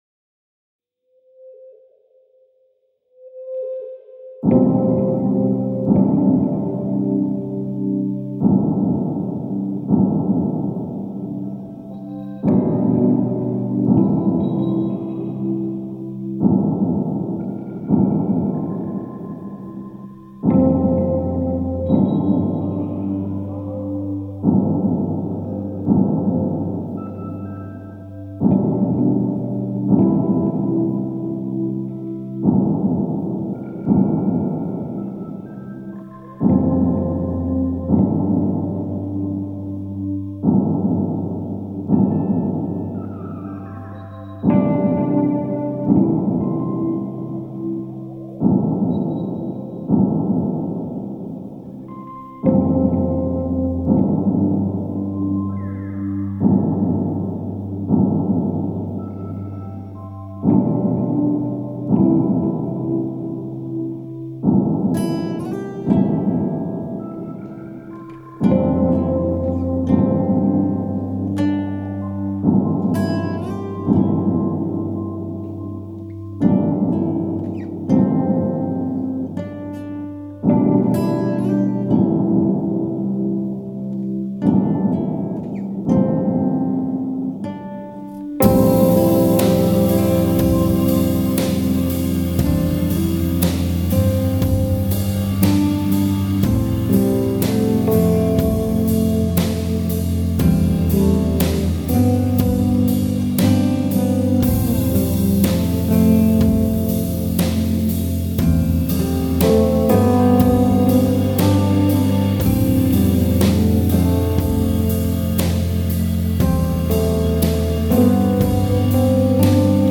ambient album